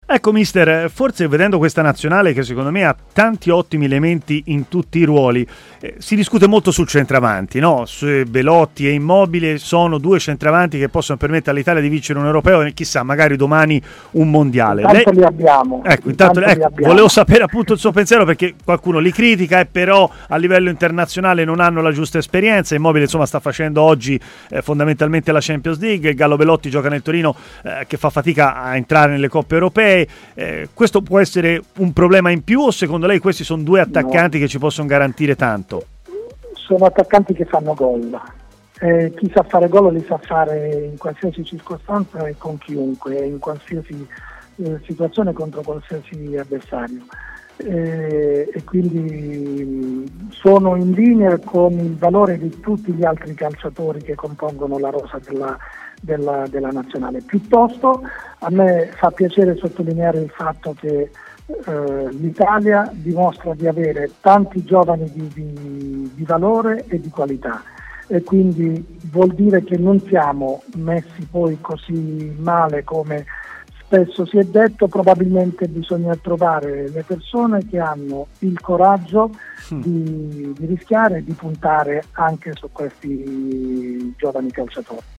Il tecnico, intervenuto ai microfoni di TMW Radio, si è espresso anche sui centravanti dell'Italia, Andrea Belotti e Ciro Immobile.